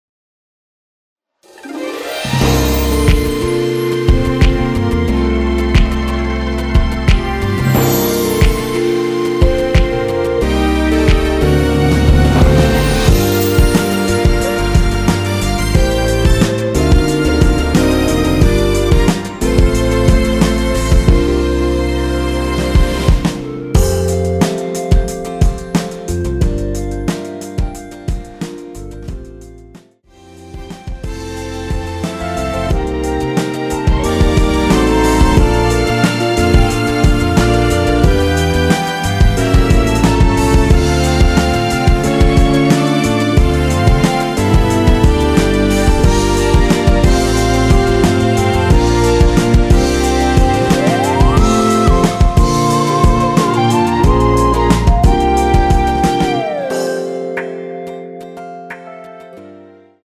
원키에서(+2)올린 멜로디 포함된 MR입니다.
앞부분30초, 뒷부분30초씩 편집해서 올려 드리고 있습니다.
중간에 음이 끈어지고 다시 나오는 이유는